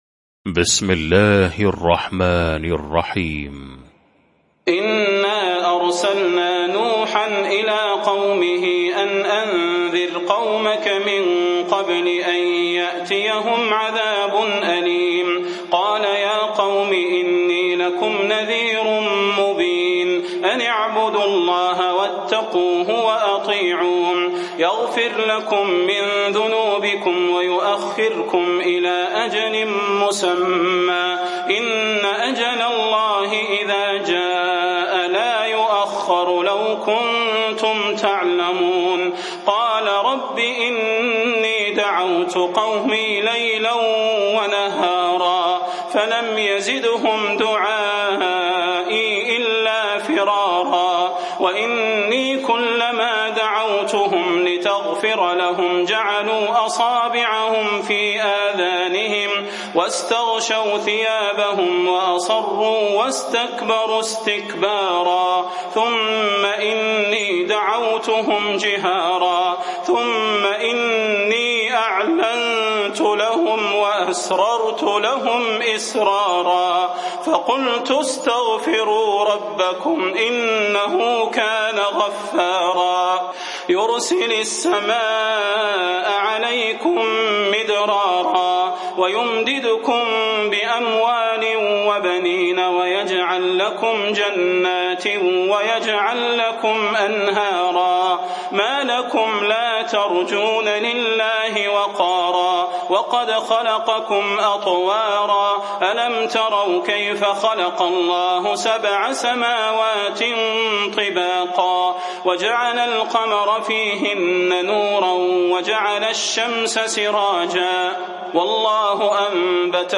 المكان: المسجد النبوي الشيخ: فضيلة الشيخ د. صلاح بن محمد البدير فضيلة الشيخ د. صلاح بن محمد البدير نوح